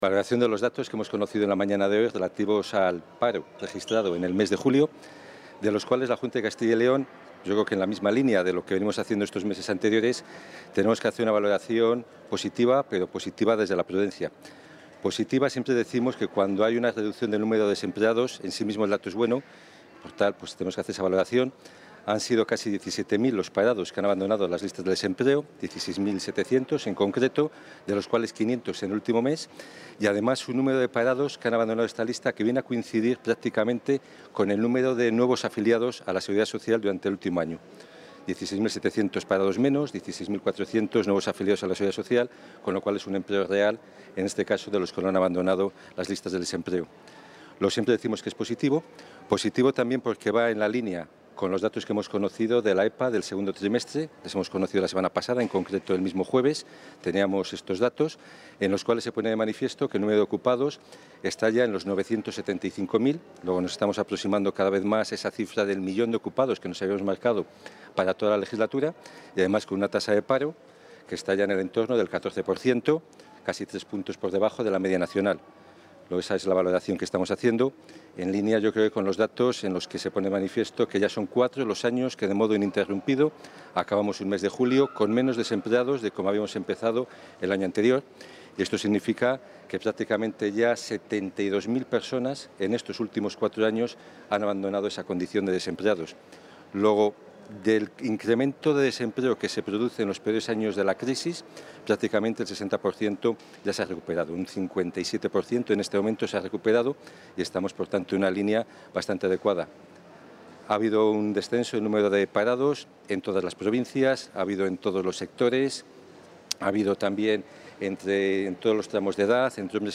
Valoración de los datos del paro de julio Contactar Escuchar 2 de agosto de 2017 Castilla y León | El viceconsejero de Empleo y Diálogo Social, Mariano Gredilla, ha valorado hoy los datos del paro del mes de julio.